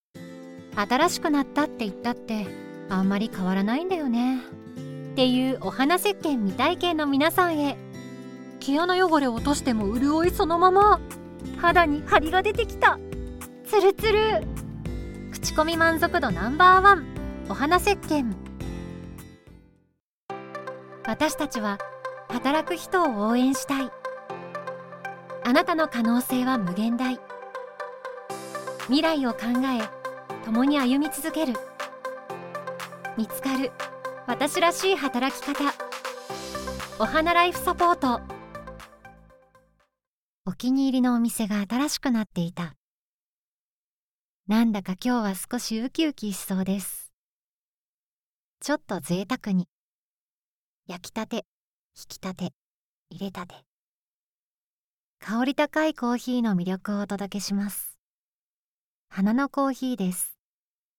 • 【ナチュラル】①化粧品　②人材派遣　③コーヒー